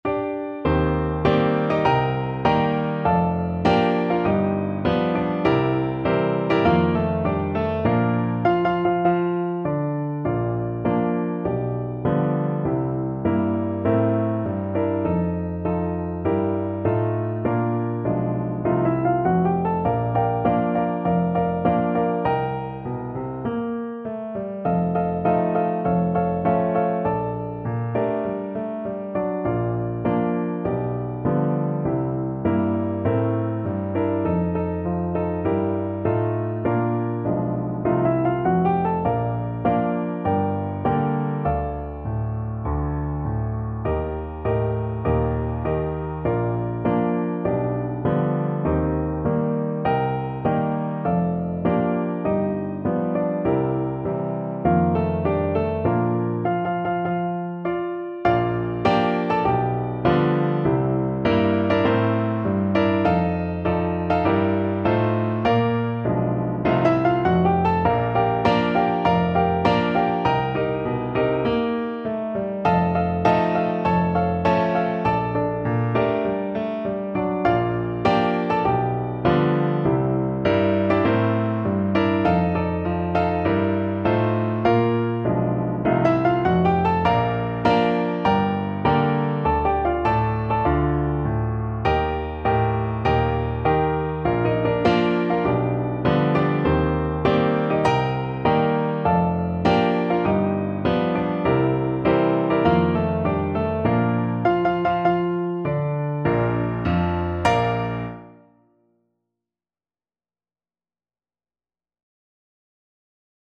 Moderato = c. 100